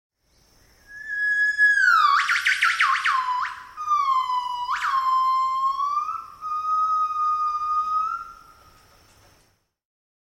Gibbon Monkey Calling Botão de Som